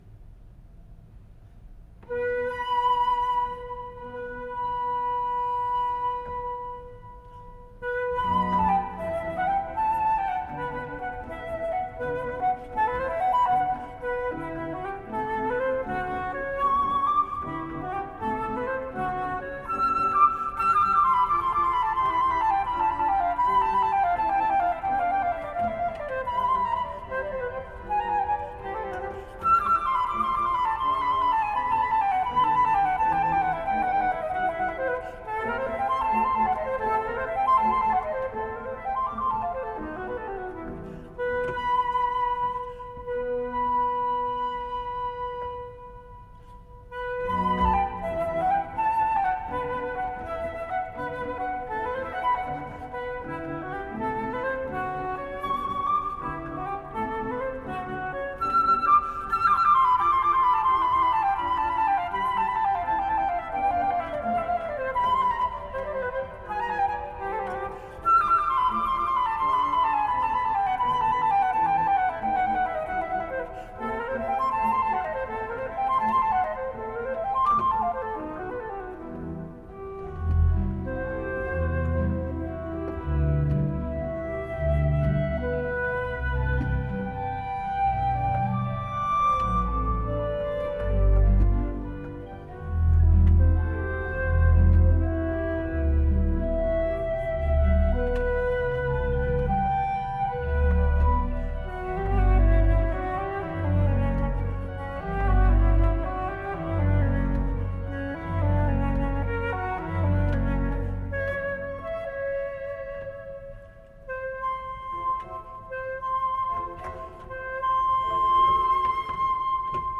flutiste
organiste